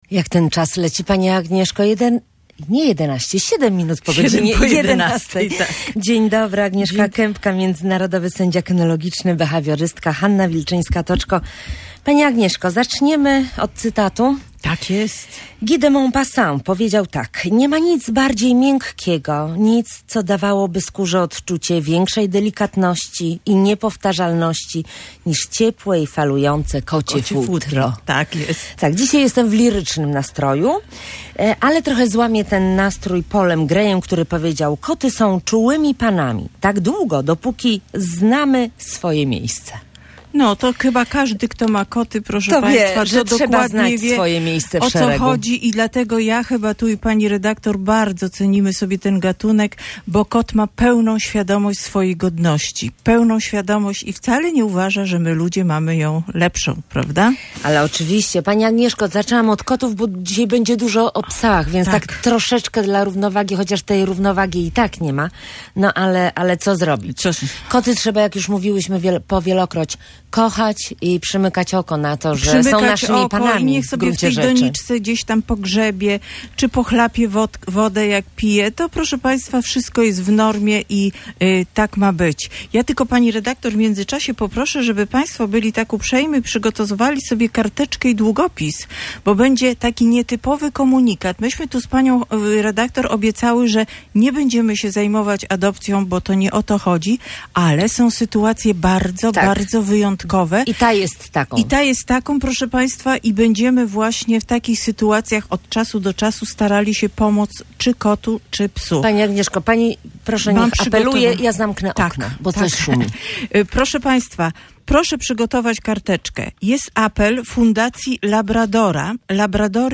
Behawiorystka w audycji opowiadała o ćwiczeniu, jakie kursanci ze swoimi czworonogami wykonują podczas szkolenia.